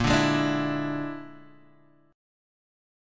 A#7b5 chord